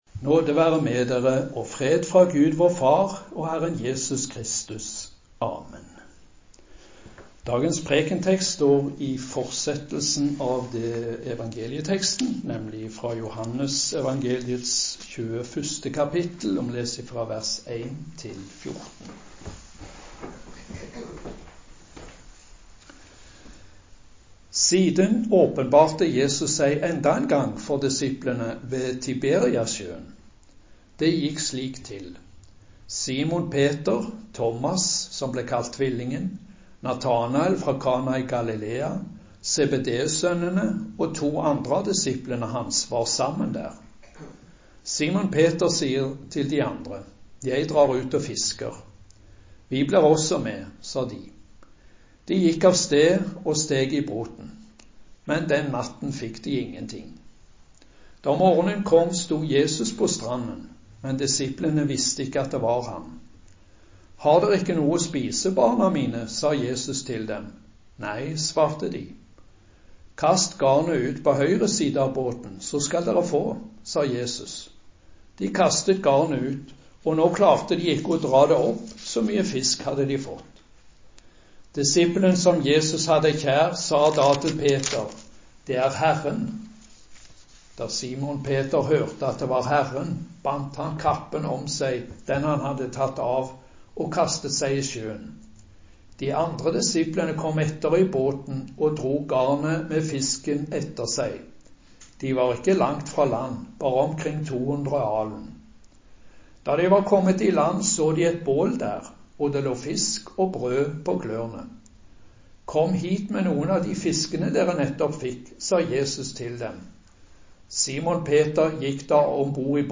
Preken på 1. søndag etter påske av pastor